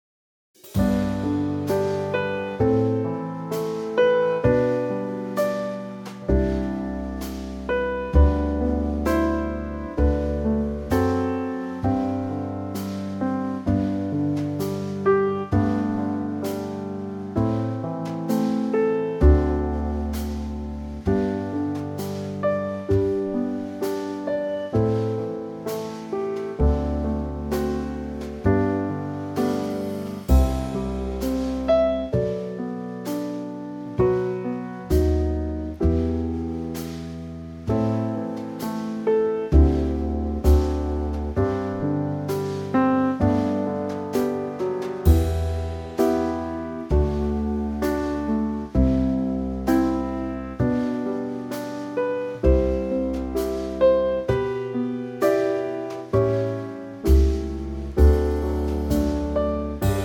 Unique Backing Tracks
4 bar intro and vocal in at 15 seconds
key - G to A - vocal range - D to A
A superb Trio arrangement